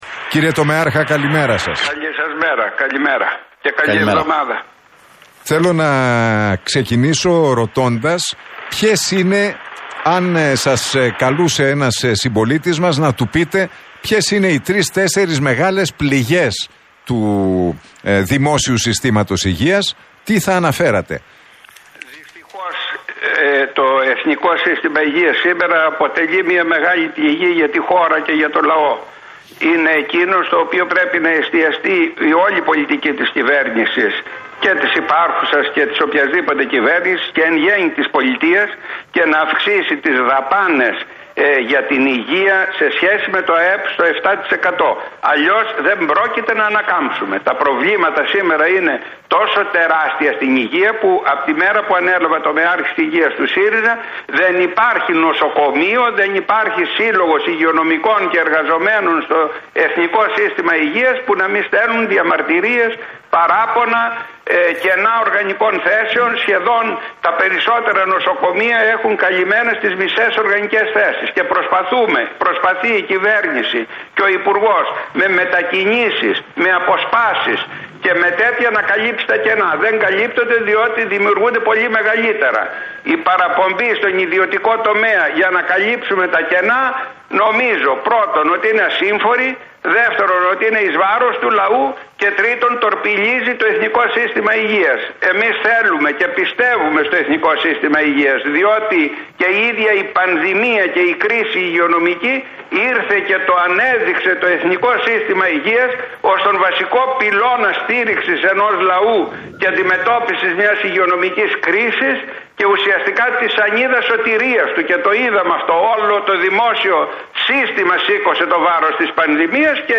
Παναγιωτόπουλος στον Realfm 97,8: Το ΕΣΥ σήμερα αποτελεί μια μεγάλη πληγή - Χρειάζεται ολοκληρωμένο σχέδιο